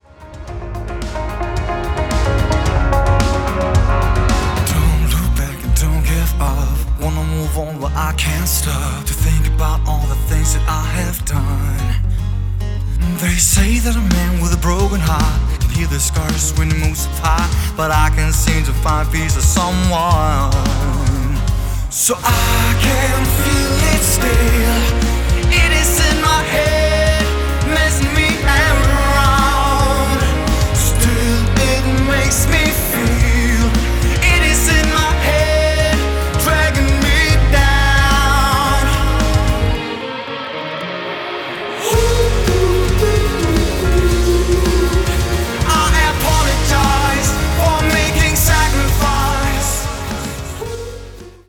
• Pop
• Rock
• Singer/songwriter
Guitar, Vokal
Original musik - Guitar & Vokal / 2 sæt